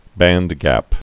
(băndgăp)